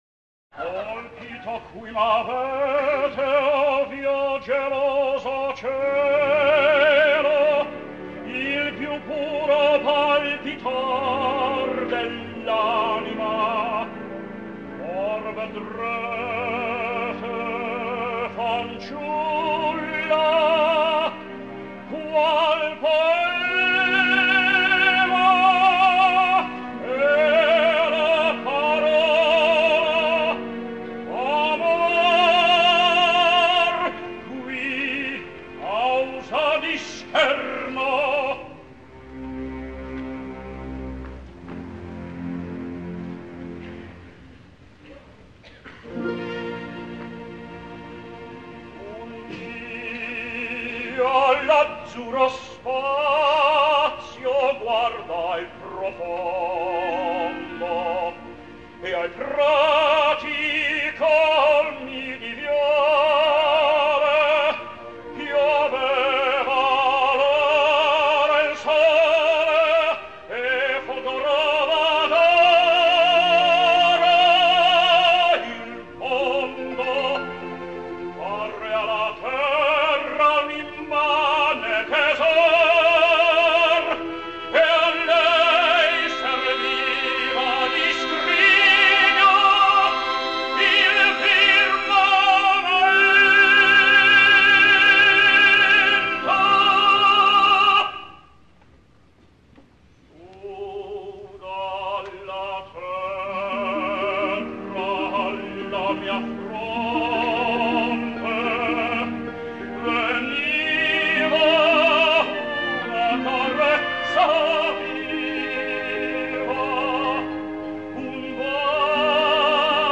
Ah! i perquè no dir-ho!, perquè les confrontacions de tenors sempre són les més disputades i porten controvèrsia, comentaris i vistes, quelcom que sempre revitalitza el blog.